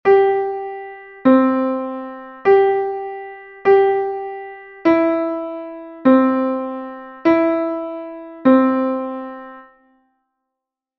G,C and E note recognition exercise 2
note_recognition_2.mp3